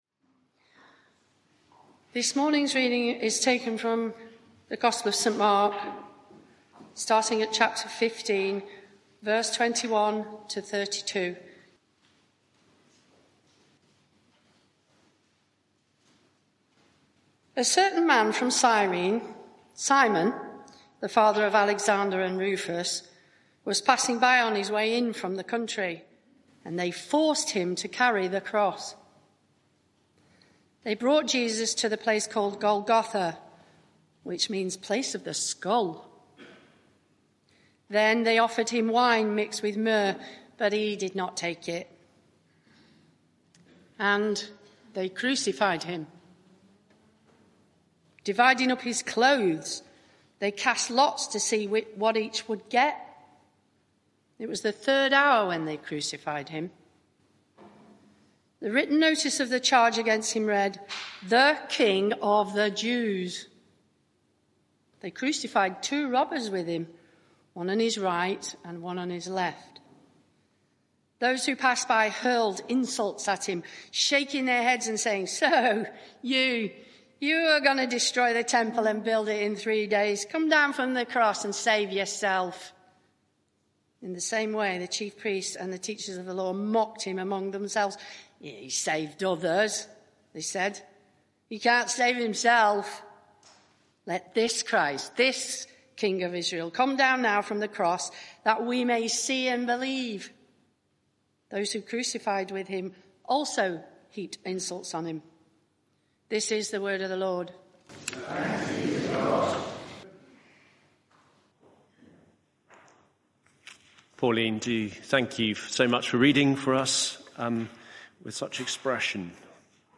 Media for 11am Service on Sun 24th Mar 2024 11:00 Speaker
Passage: Mark 15:21-32 Series: The Road to Glory Theme: Sermon (audio) Search the media library There are recordings here going back several years.